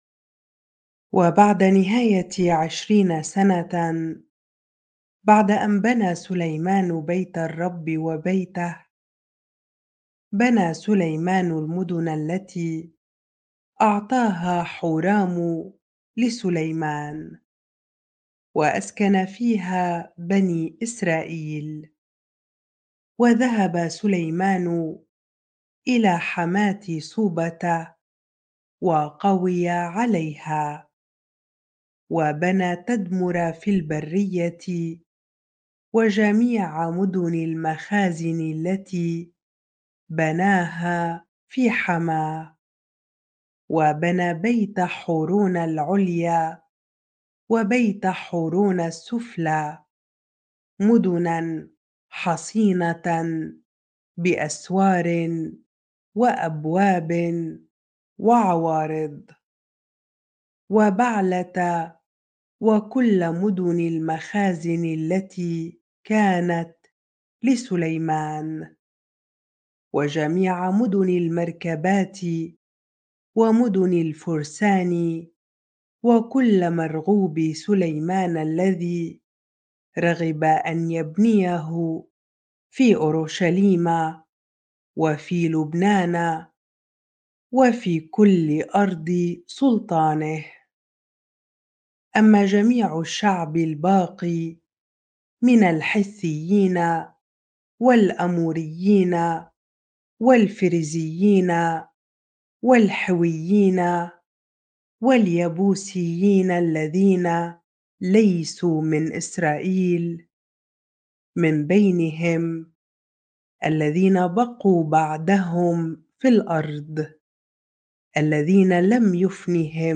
bible-reading-2 Chronicles 8 ar